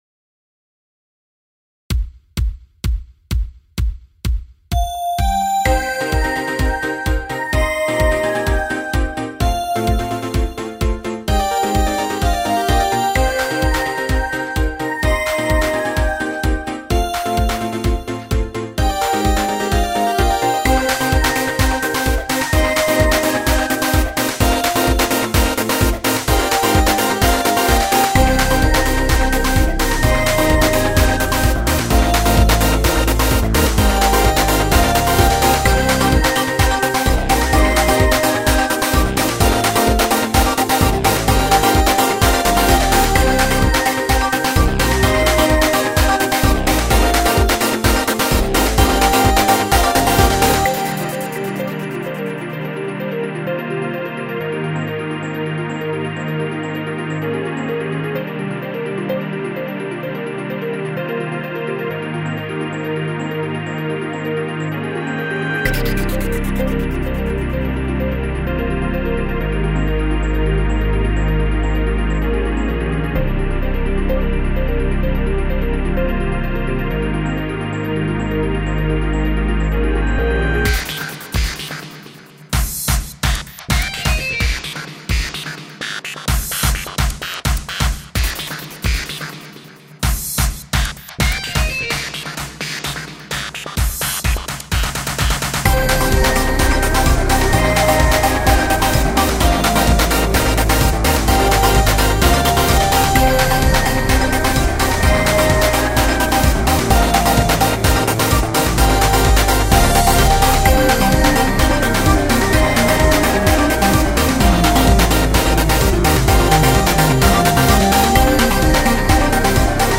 EDMエレクトロニカ明るい
BGM